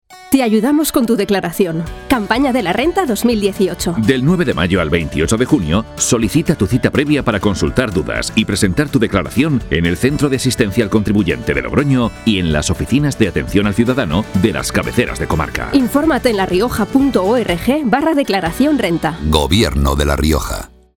Elementos de campaña Cuñas radiofónica Cuña de 20".